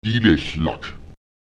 Lautsprecher delexlak [ÈdelE§lak] das Essen, das Getränk (etwas, das gegessen/getrunken wird)